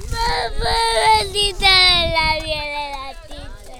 crying11.wav